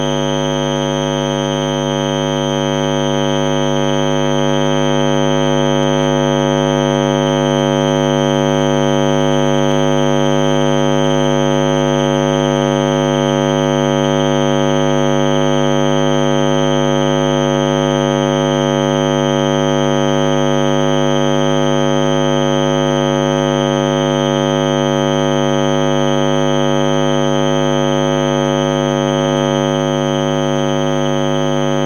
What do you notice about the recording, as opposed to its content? ultrasound chatter (further editing less noise)